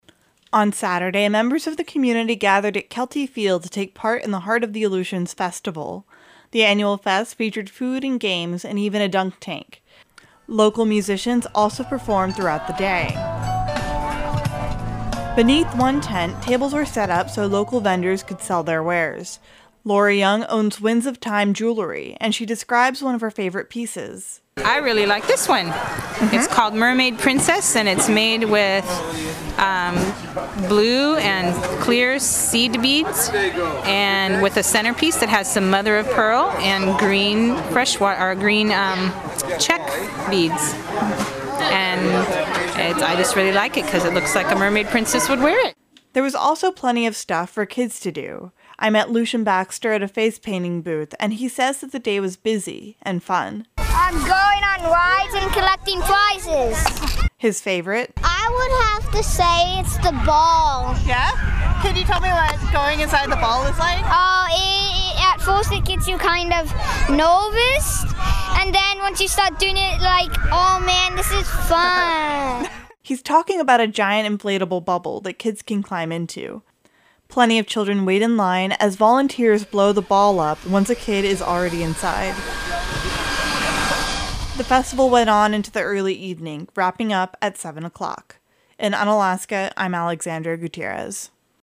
Unalaska, AK – On Saturday, members of the community gathered at Kelty field to take part in the Heart of the Aleutians festival. The annual fest featured food and games and even a dunk tank. Local musicians also performed throughout the day.